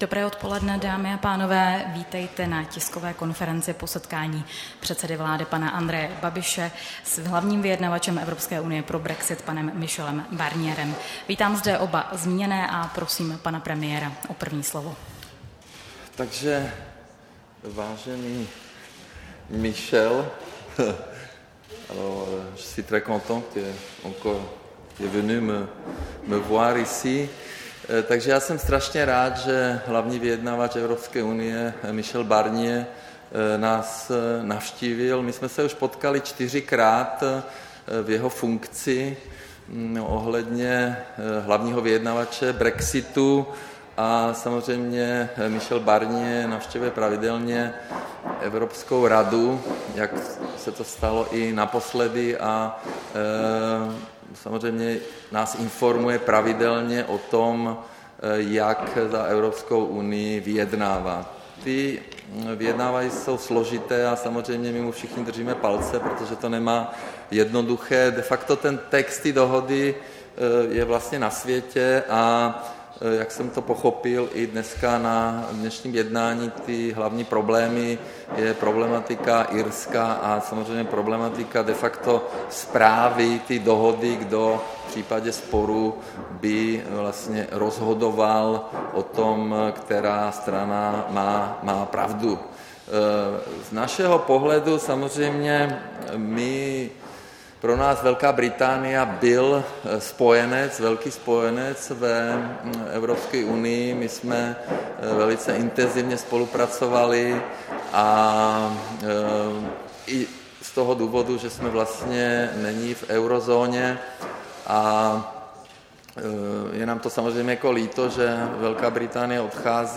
Tisková konference po jednání předsedy vlády Andreje Babiše s hlavním vyjednavačem EU pro brexit Michelem Barnierem, 12. dubna 2018